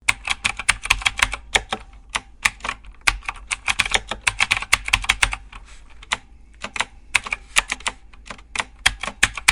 Mechanical Keyboard
Foley
yt_BYIw4sDs4ic_mechanical_keyboard.mp3